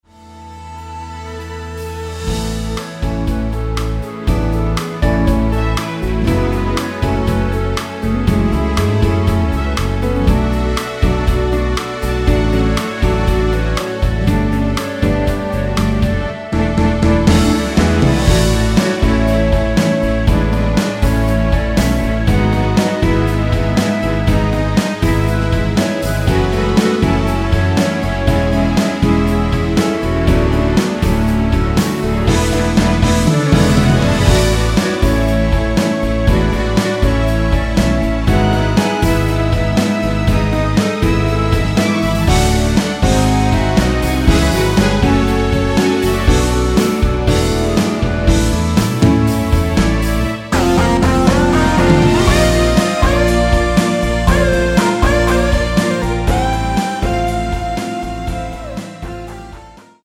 원키에서(-2)내린 멜로디 포함된 MR입니다.
앨범 | O.S.T
앞부분30초, 뒷부분30초씩 편집해서 올려 드리고 있습니다.
중간에 음이 끈어지고 다시 나오는 이유는